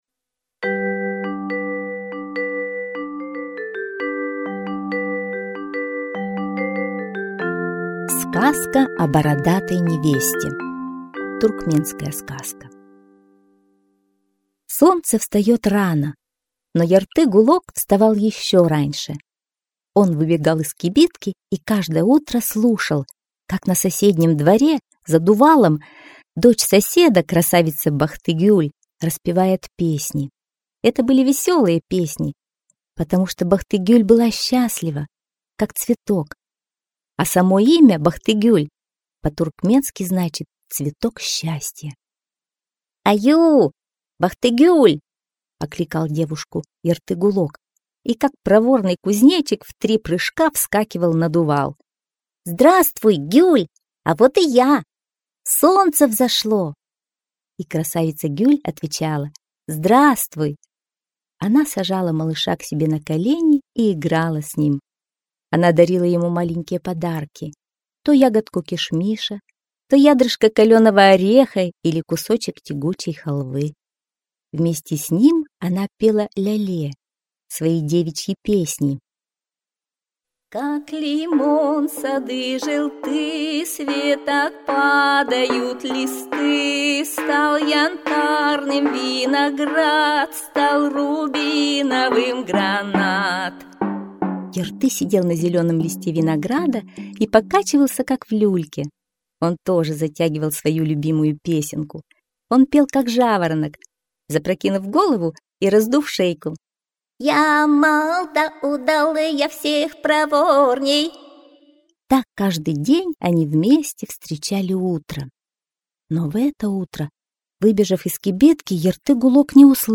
Сказка о бородатой невесте — туркменская аудиосказка. Как малыш Ярты-гулок помог дочери соседа избавиться от старого жениха Мухаммеда.